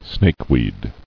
[snake·weed]
Snake"weed` , n. (Bot.)